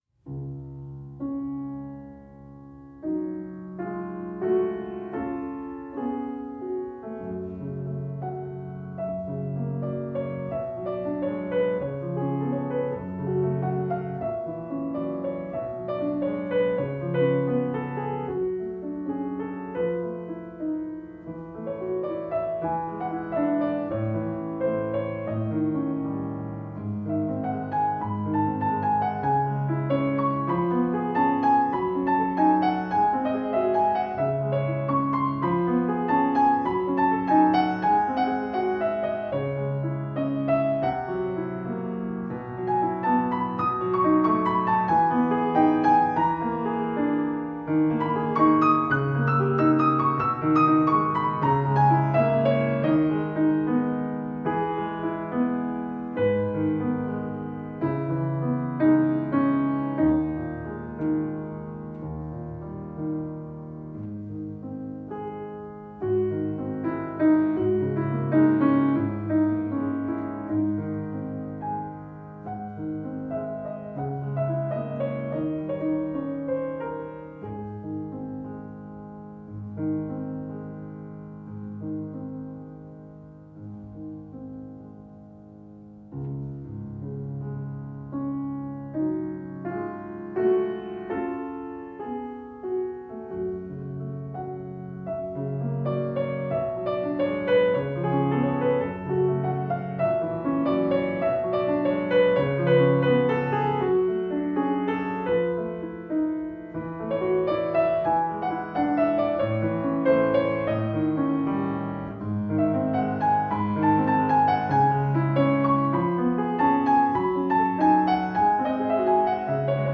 Postlude  F. Chopin, Nocturne in F# minor Op. 48 No. 2
piano